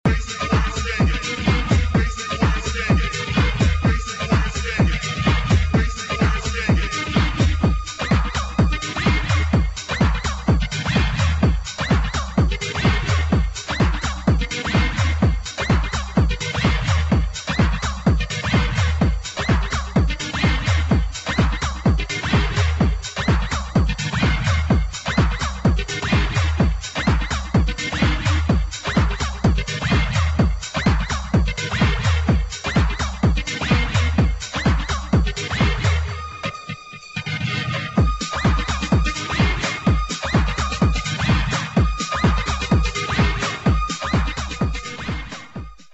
HOUSE | DISCO